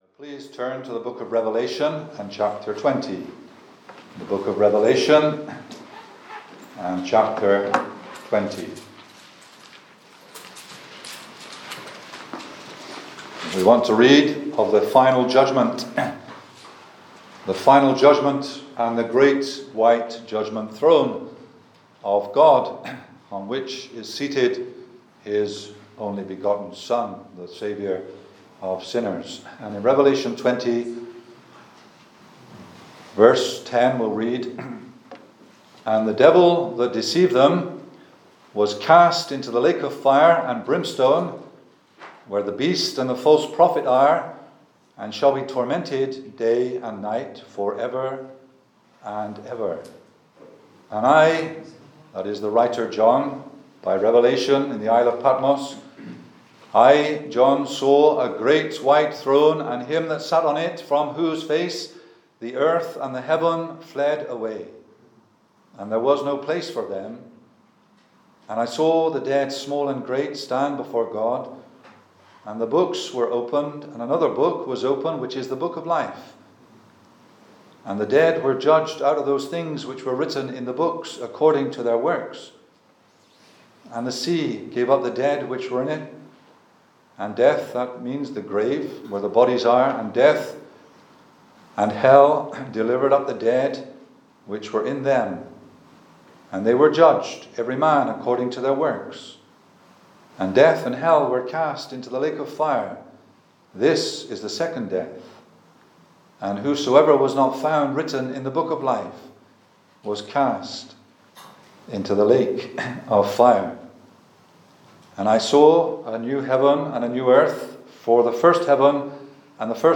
Reading from Revelation 20, the speaker preaches solemnly concerning the final judgement that awaits all who die still in their sin; the Great White Throne. The speaker considers an ‘unforgettable sight’, ‘unforgiven sinners’, an ‘unavoidable sentence, an ‘unlimited Saviour’ and an ‘unconditional surrender’.
Service Type: Gospel